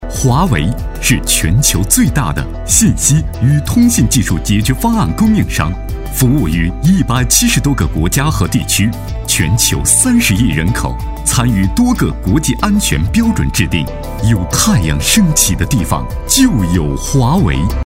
科技感 电子科技宣传片配音
大气磁性男音，偏年轻声线。